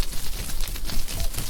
tree.ogg